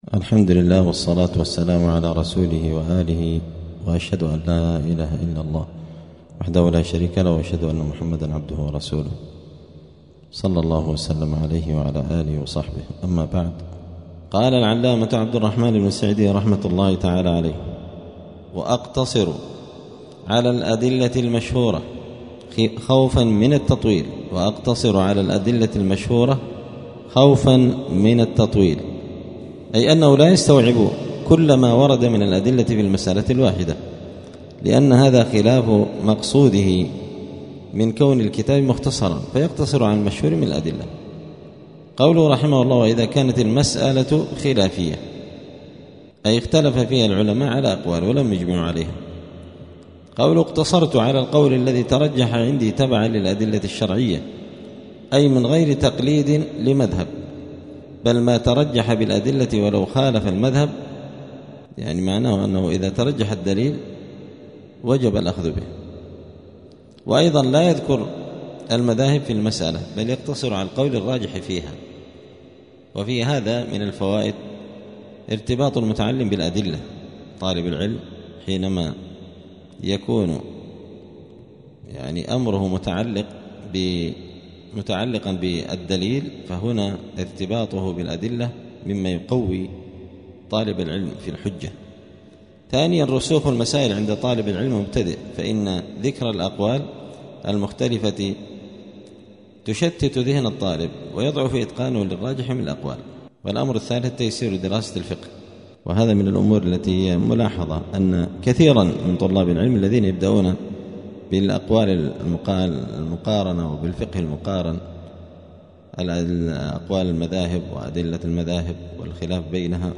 *الدرس الثامن (8) {الأحكام التكليفية}*